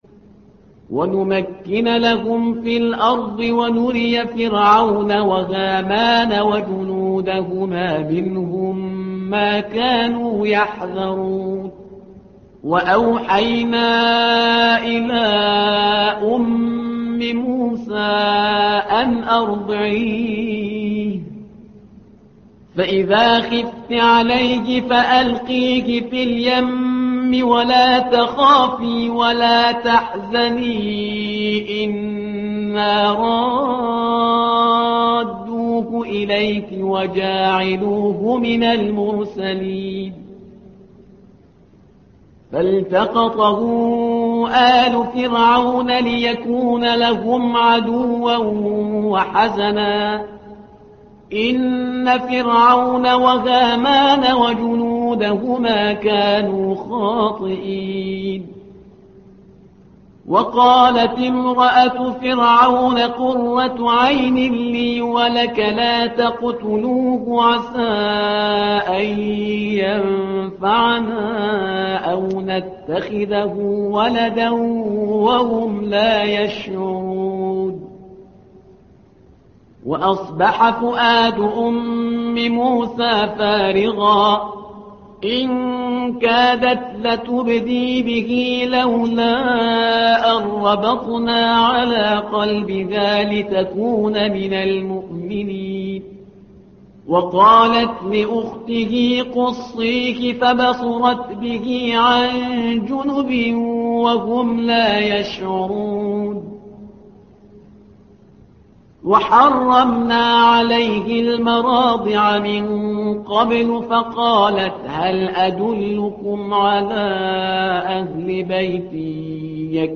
تحميل : الصفحة رقم 386 / القارئ شهريار برهيزكار / القرآن الكريم / موقع يا حسين